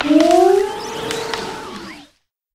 Cri de Virevorreur dans Pokémon HOME.